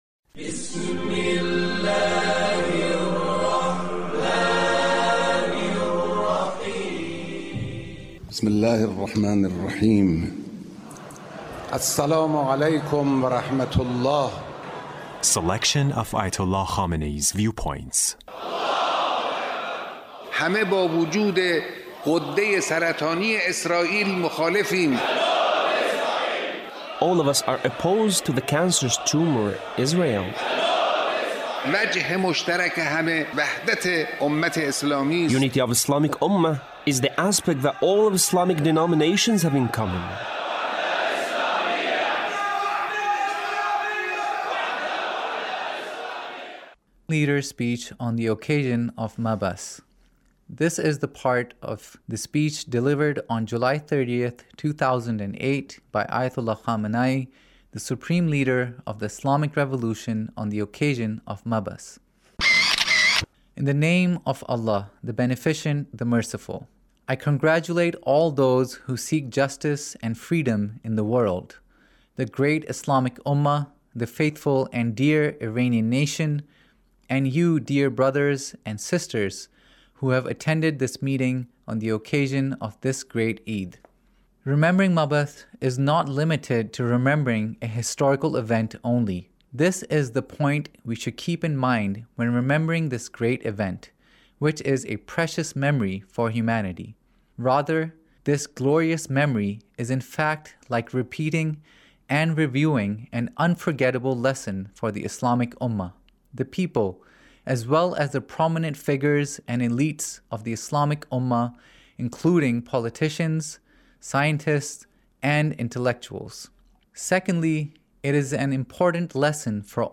Leader's Speech on Mab'ath